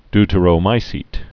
(dtə-rō-mīsēt)